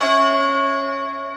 Key-bell_88.1.1.wav